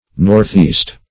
Northeast \North`east"\, n.